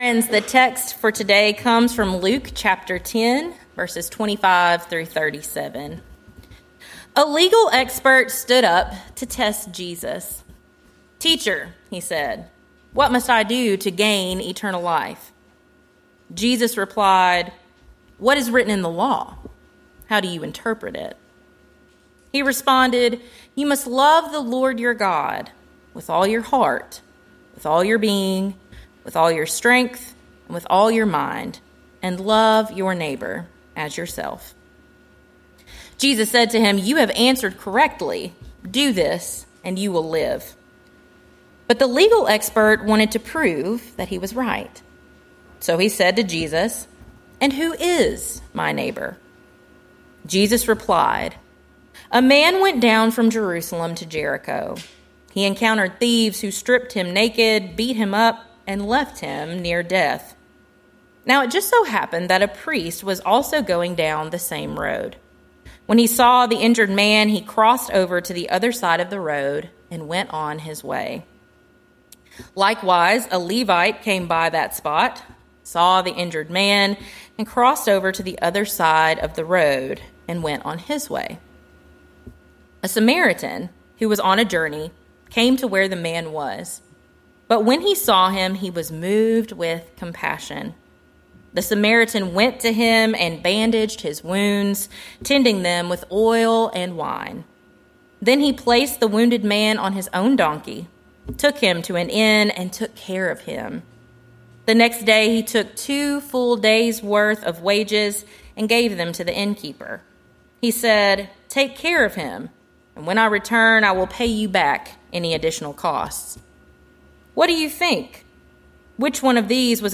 Sermons | Hilldale United Methodist Church